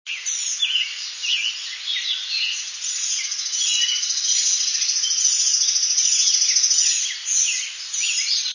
Chipping Sparrow
Chipping Sparrow, Indian Head Point, Mohican Outdoor Center, Delaware Water Gap 6/24/02, 5:30 a.m., song (34kb)
sparrow_chipping547.wav